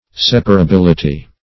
Separability \Sep`a*ra*bil"i*ty\